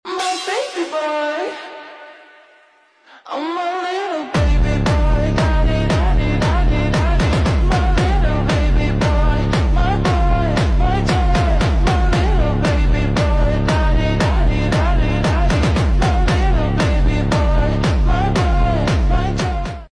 Hardstyle